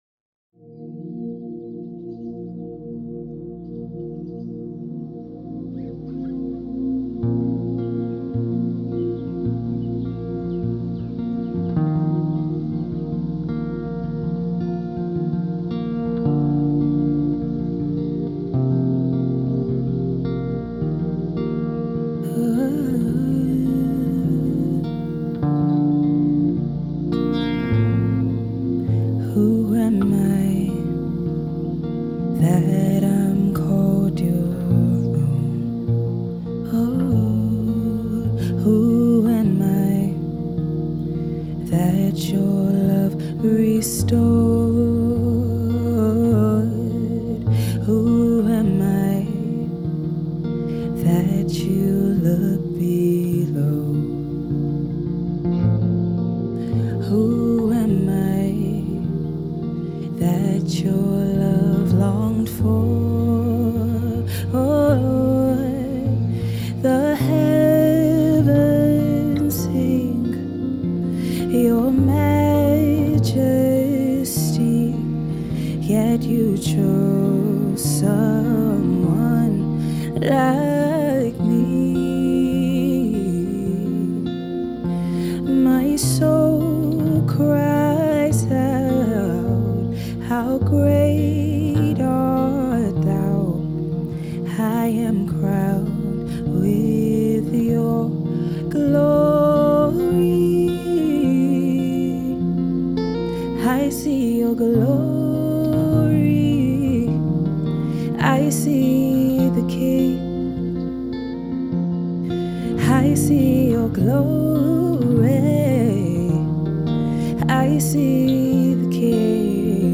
Universal Gospel
The passionate and soulful gospel music artiste